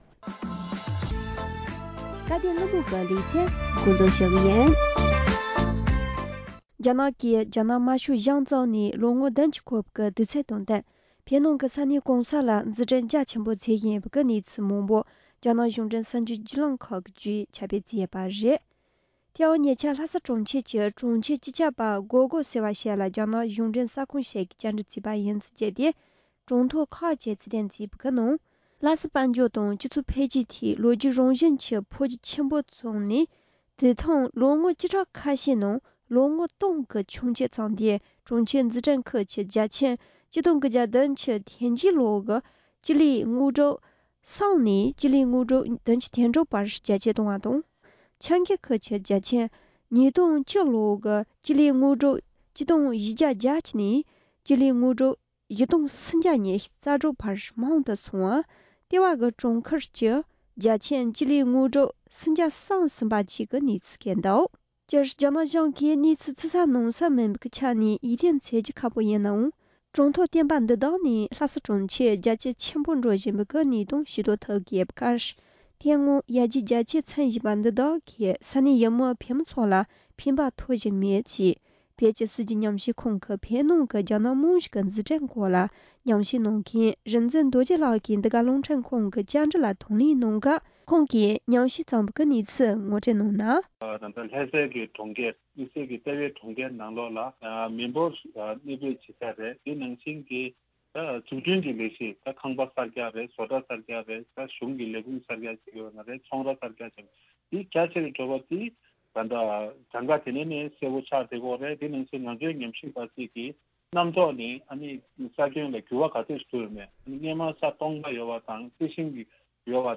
སྒྲ་ལྡན་གསར་འགྱུར།
༄༅།།སྐབས་དོན་གླེང་མོལ་གྱི་ལེ་ཚན་ནང་།